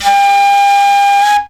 FLUTELIN09.wav